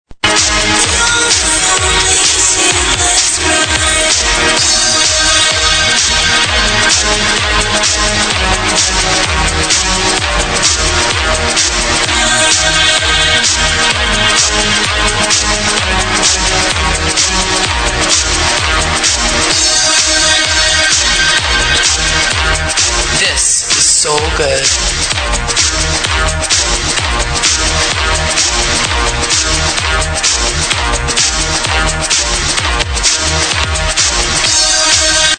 Proggressive? massive vocal track to id